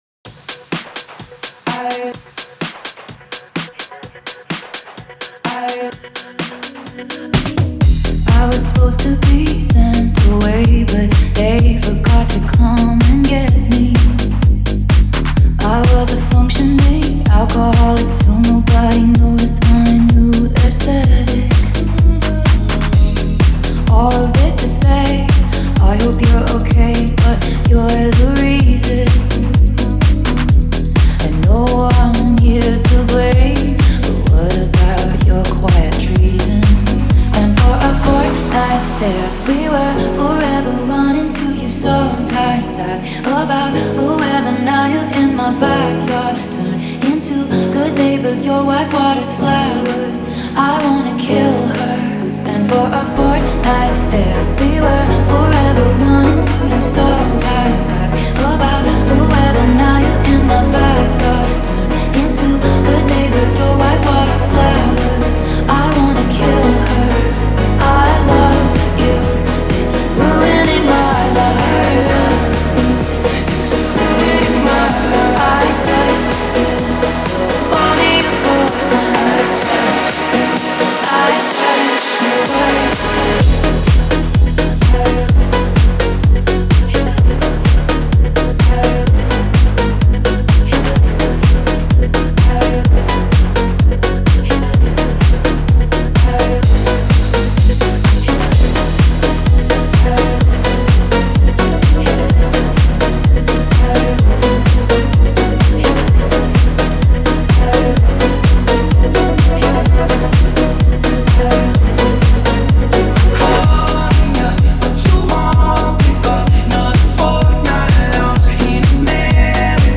Pop, Synth-Pop, Electro-Pop, Singer-Songwriter